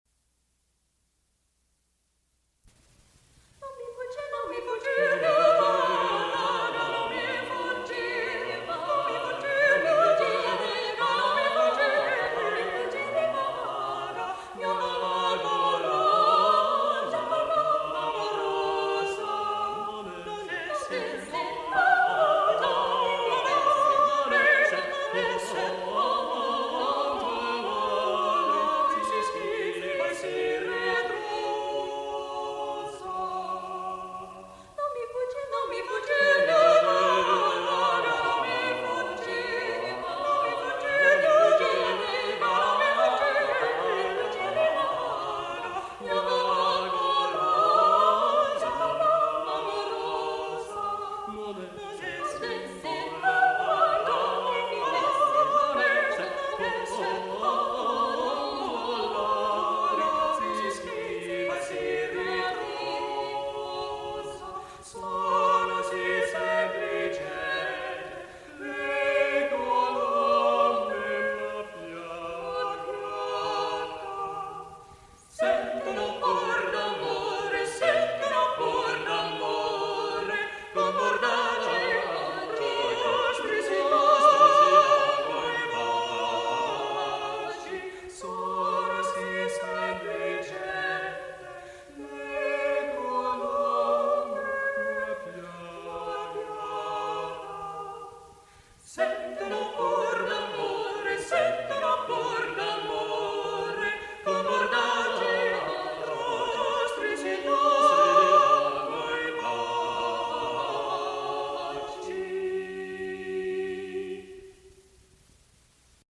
| Vocal Ensemble, 'Northern Forests' 1980